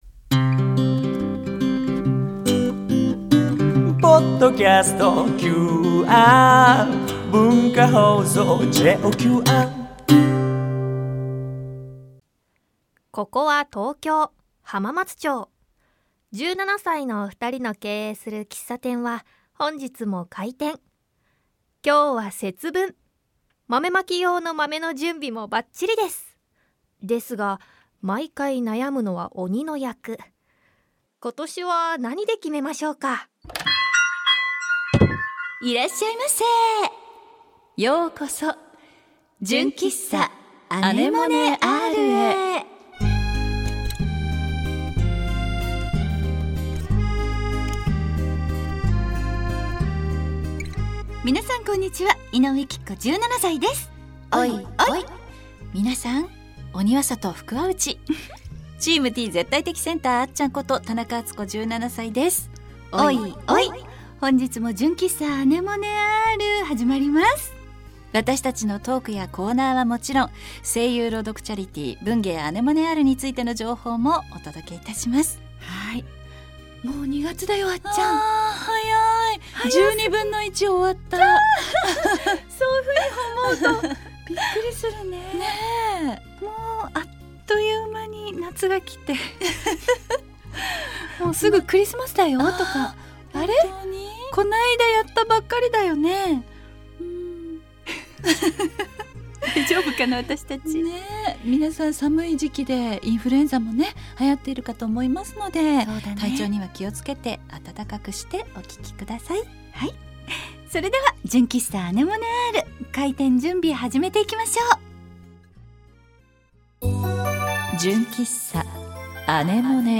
声優チャリティー文芸あねもねRのPodcast番組【純喫茶あねもねR】 第42回更新です♪ ふんわりトークをお楽しみくださいね♪